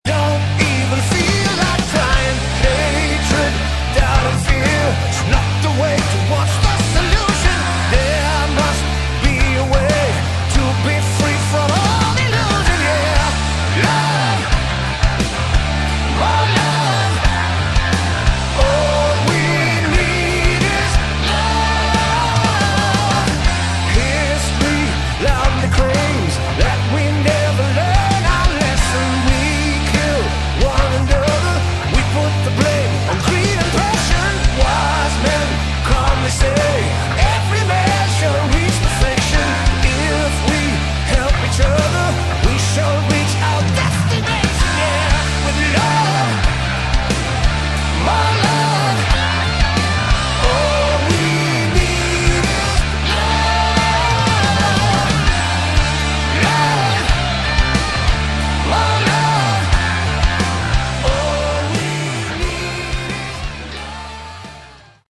Category: Hard Rock
vocals
keyboards
bass
guitars
drums